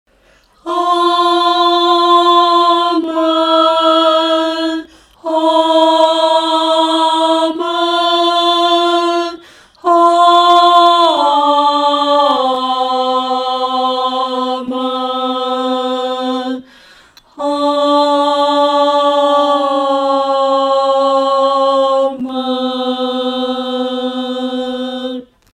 女低
本首圣诗由网上圣诗班录制